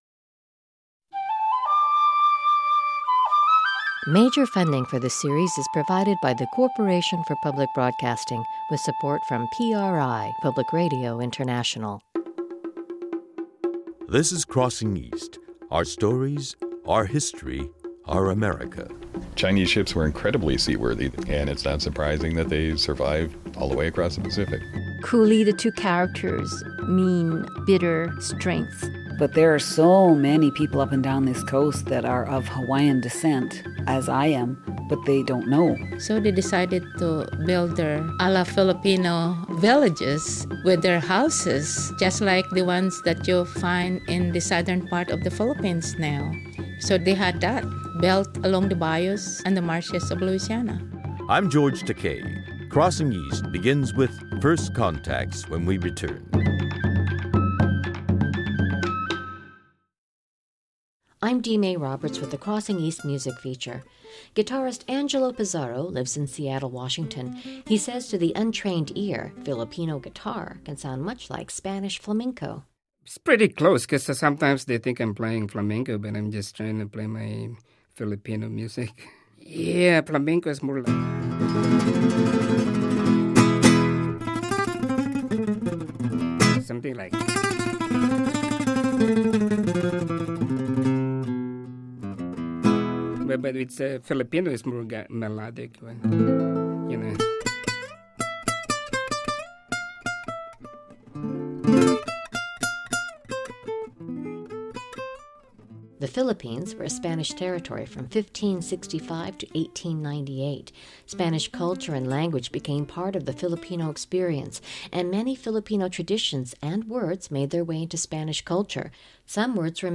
Archival Material